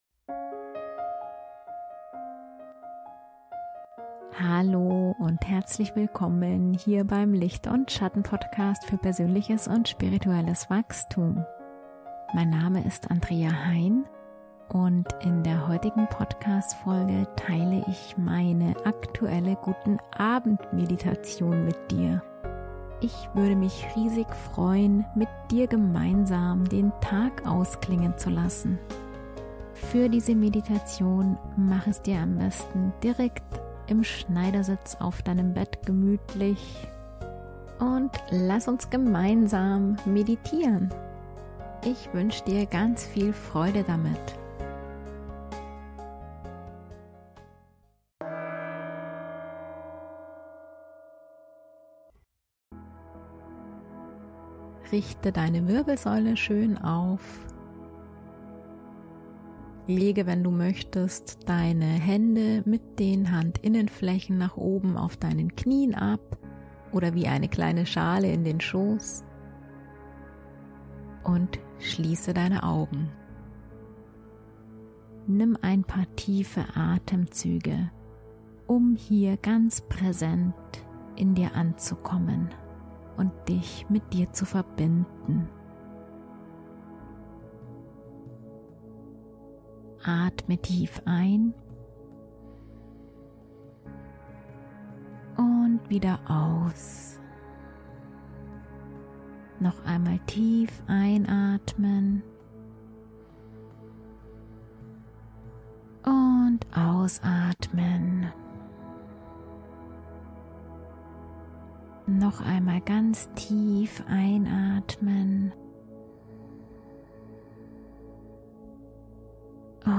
Heilsame Guten-Abend-Meditation für Dich, Dein Herz und Dein inneres Kind ~ Licht & Schatten Podcast für persönliches und spirituelles Wachstum Podcast
In dieser sehr friedvollen und heilsamen Meditation, verbinden wir uns ganz bewusst und liebevoll mit unserem Herzen und dem kindlichen Anteil in uns – dem Teil in uns, der verletzt ist, der...